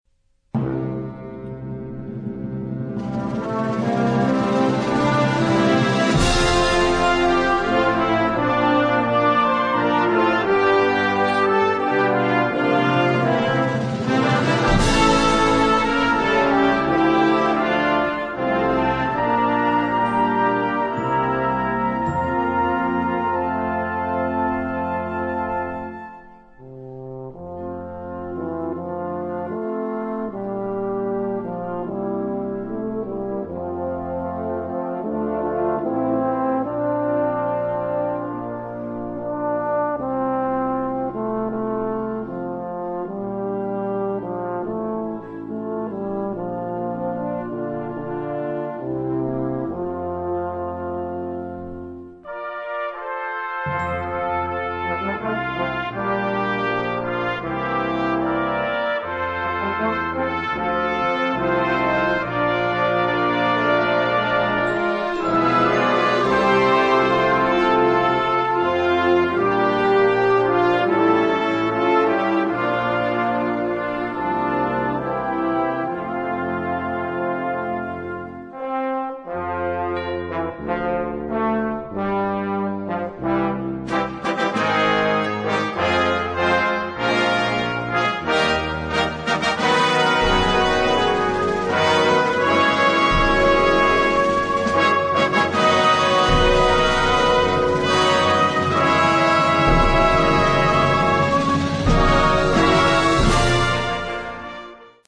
beautiful and lush setting
Partitions pour orchestre d'harmonie.